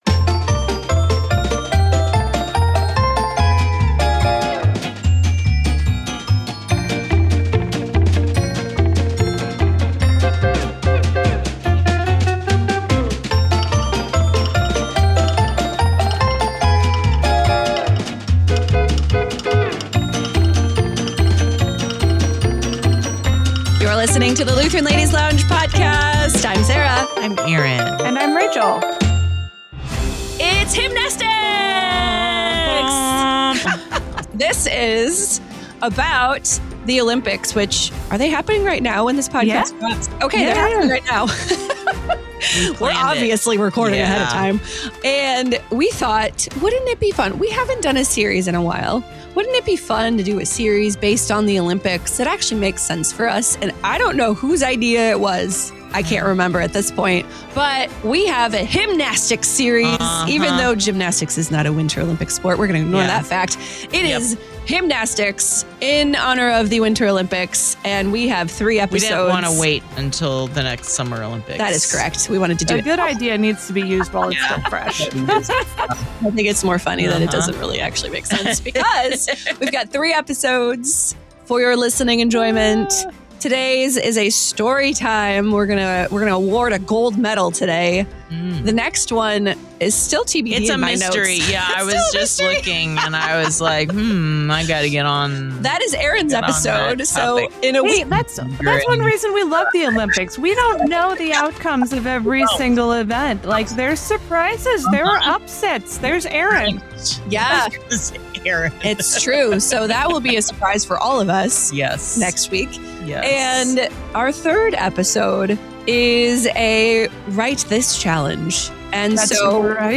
In honor of the world’s most prestigious sporting event, the Lutheran Ladies are launching their own Winter Hymnastics series. Over the next three episodes, they’ll laugh, they’ll cry, they’ll sweat (literally), and above all, they’ll sing as they celebrate some of the greatest hymns and hymnwriters past, present, and even yet to come.